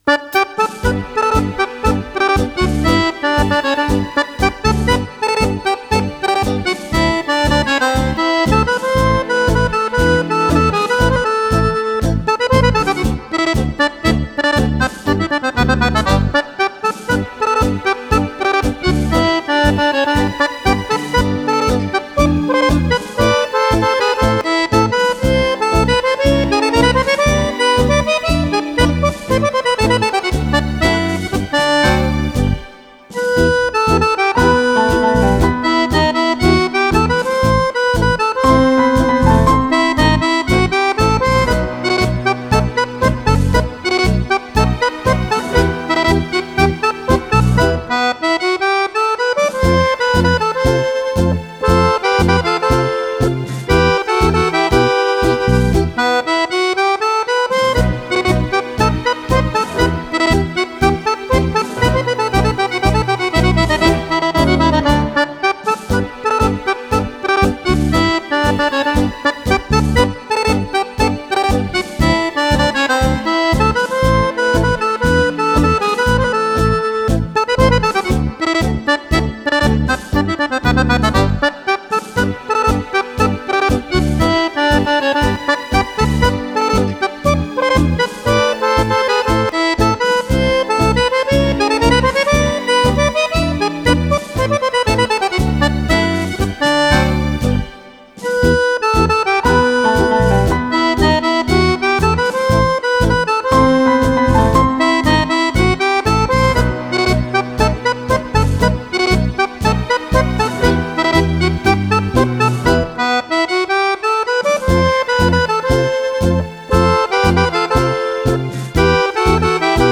Tango
e 12 ballabili per Fisarmonica solista